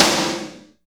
51.09 SNR.wav